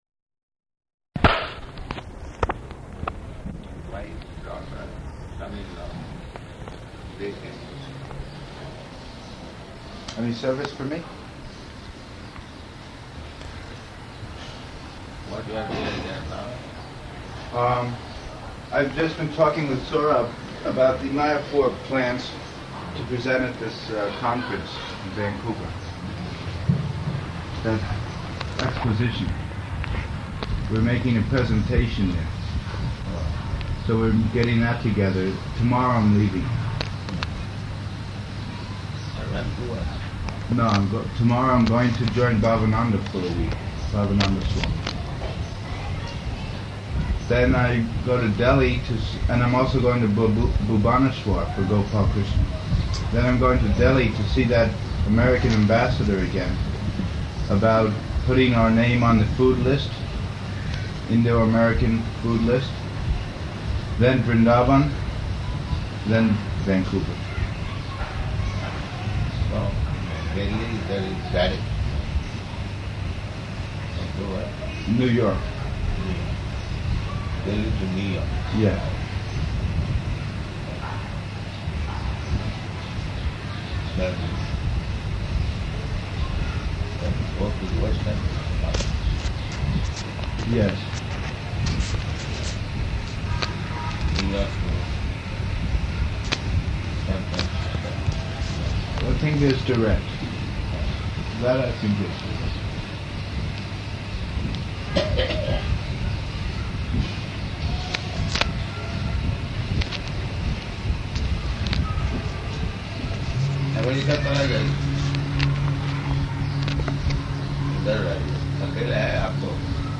Room Conversation
Room Conversation --:-- --:-- Type: Conversation Dated: February 6th 1974 Location: Vṛndāvana Audio file: 740206R1.VRN.mp3 Prabhupāda: My Godbrother's son-in-law, they came to see me.